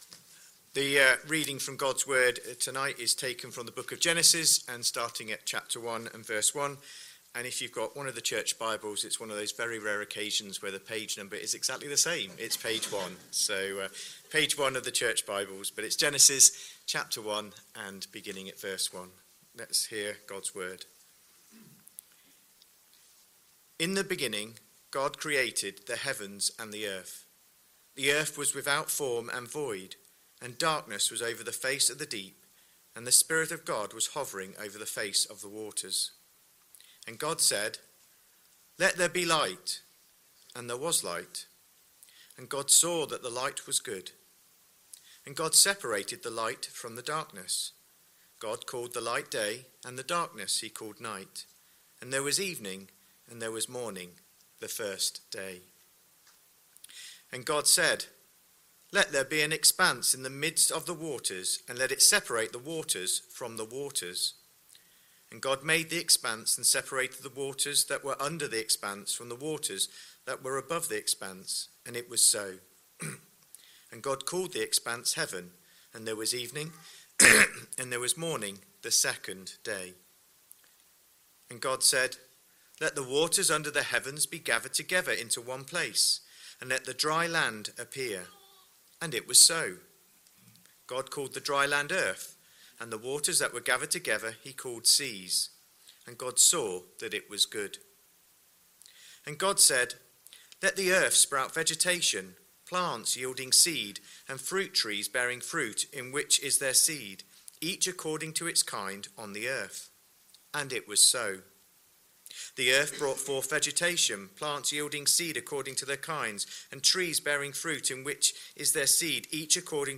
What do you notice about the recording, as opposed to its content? Sunday Evening Service Speaker